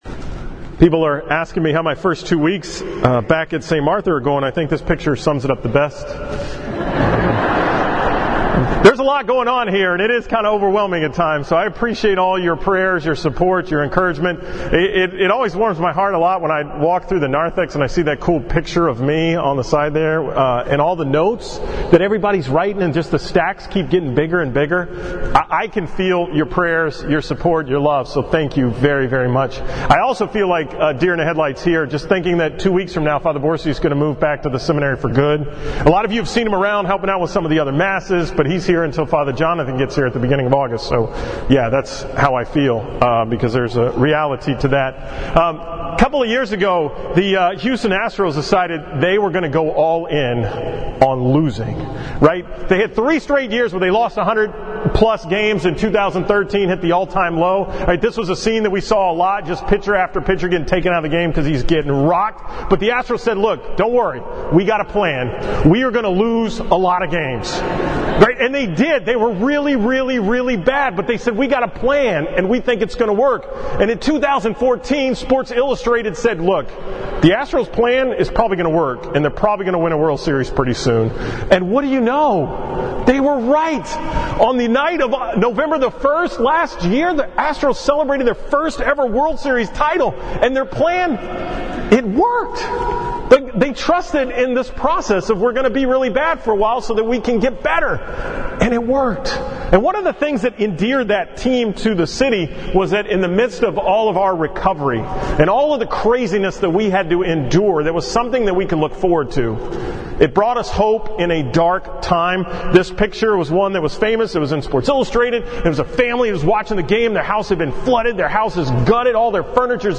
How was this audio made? From the 5:30 pm Mass at St. Martha’s on July 15, 2018